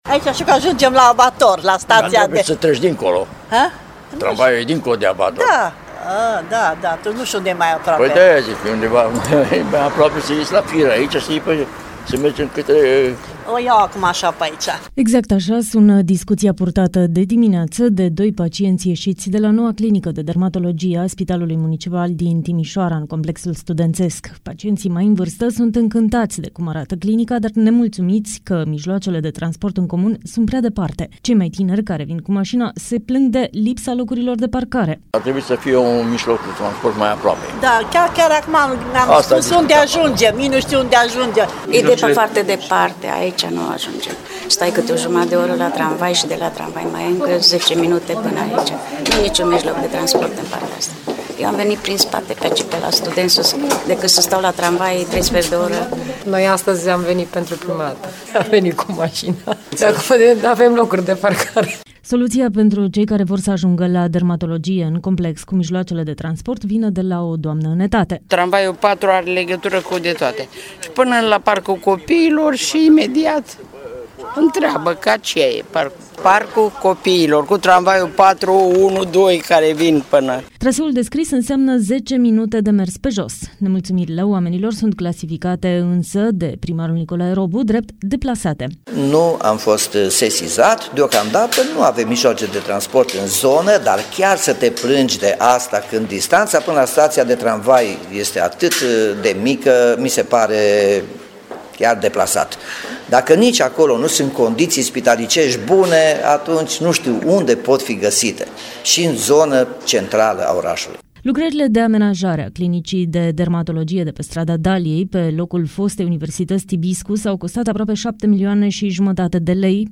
Primarul Timișoarei, Nicolae Robu, consideră că nemulțumirile oamenilor sunt deplasate: